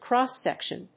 アクセントcróss sèction